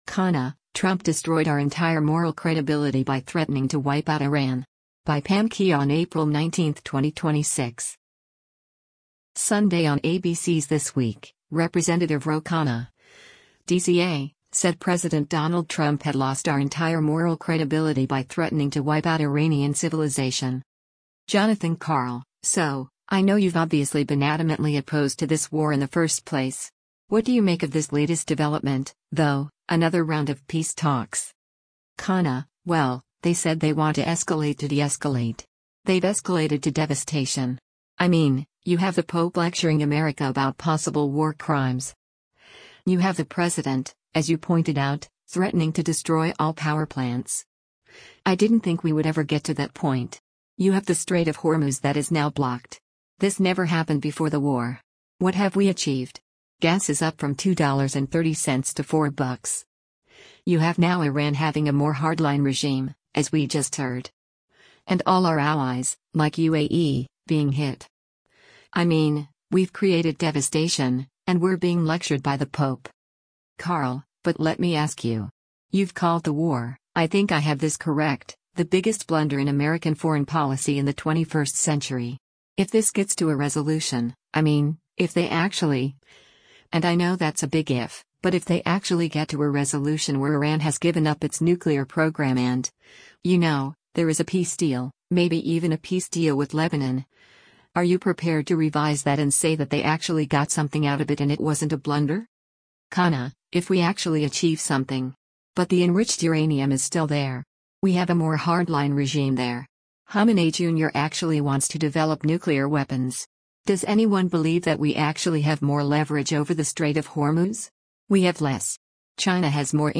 Sunday on ABC’s “This Week,” Rep. Ro Khanna (D-CA) said President Donald Trump had “lost our entire moral credibility” by threatening to wipe out Iranian civilization.